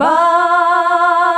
BAH UNI F.wav